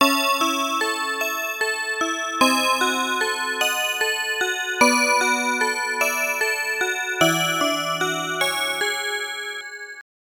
発音サンプル
分散発音記述を行った音 , FMP7用ソース
2.は、1をこのツールを使用して分散発音化したものです。
音色を含めて同じものですが、エフェクタでも入れたのでは?と感じられる音になります。
上記のサンプルフレーズの生成にはFMP7というドライバを使用しています。
このドライバは優れた能力を持ち、多チャンネルの発音が可能なため、最終的な発音数は若干多めに確保しています。